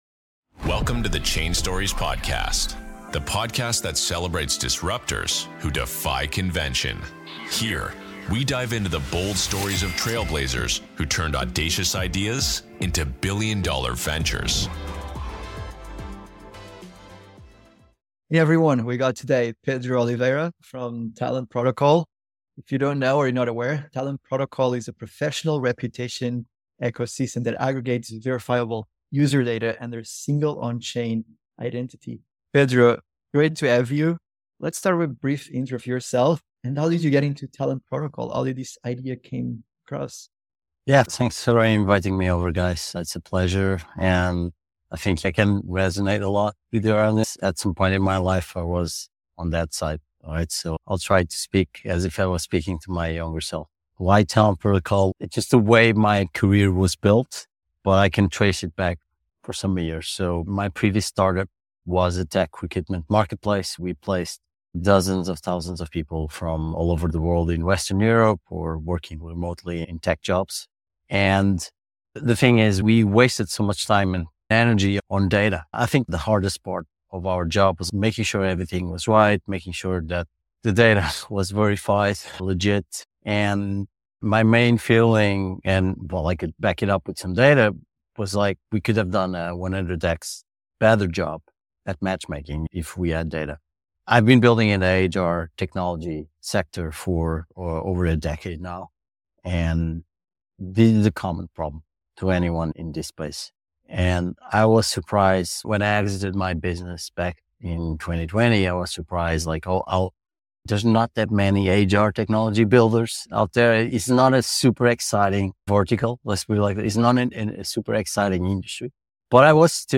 Building the LinkedIn of Web3 - Interview with Talent Protocol